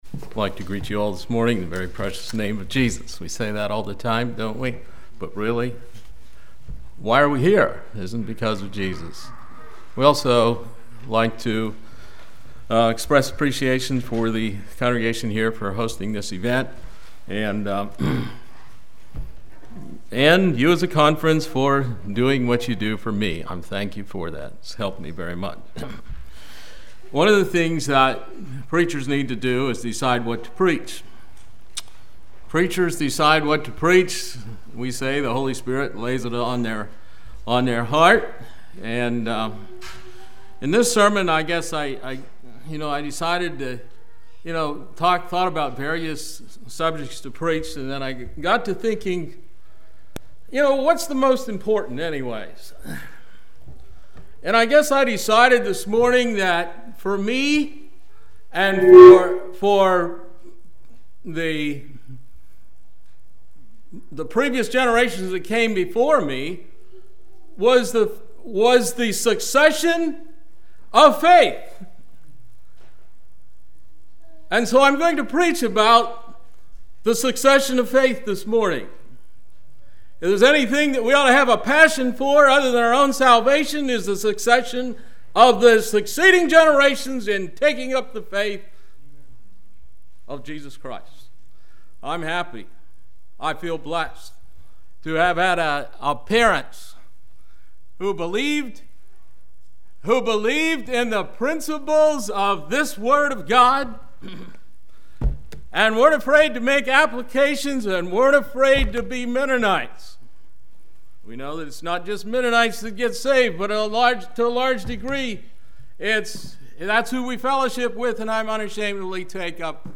2015 Sermon ID